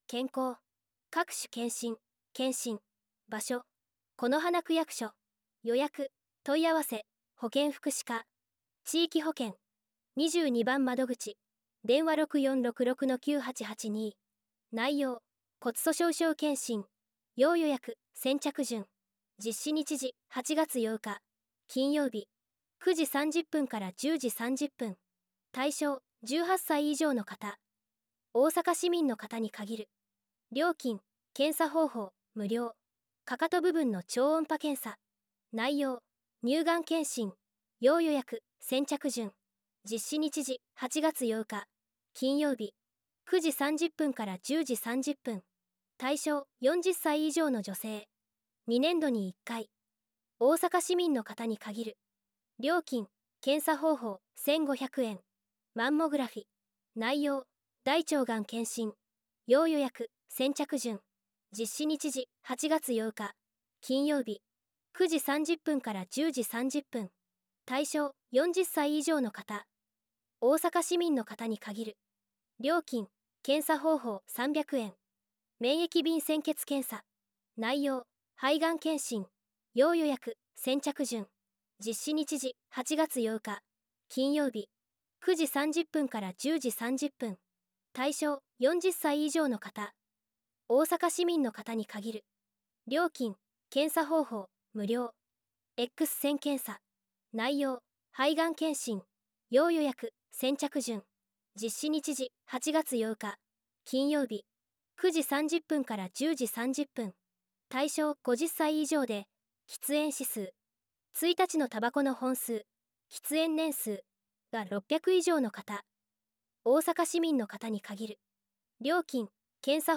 音声版　広報「このはな」令和7年7月号